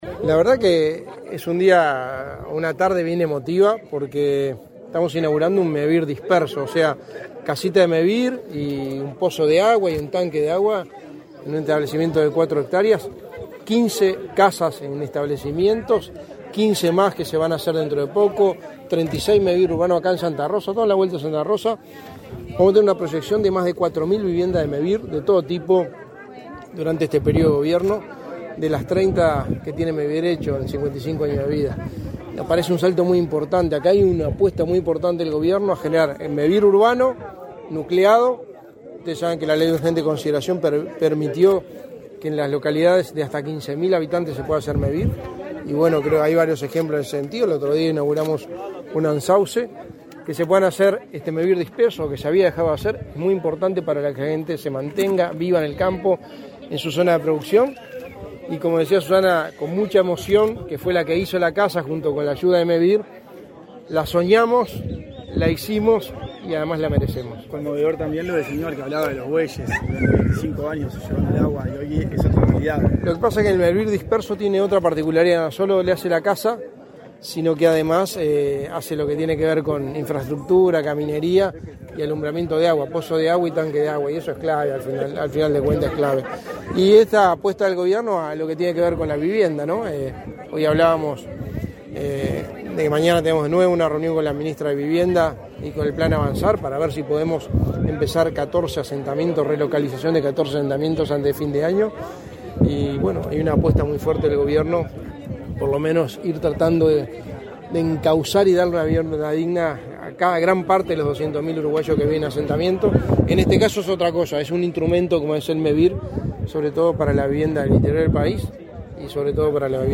Declaraciones a la prensa del secretario de Presidencia, Álvaro Delgado
Tras participar en la inauguración de viviendas de Mevir en la localidad de Santa Rosa, en el departamento de Canelones, este 29 de noviembre, Delgado